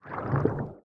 Minecraft Version Minecraft Version latest Latest Release | Latest Snapshot latest / assets / minecraft / sounds / entity / squid / ambient1.ogg Compare With Compare With Latest Release | Latest Snapshot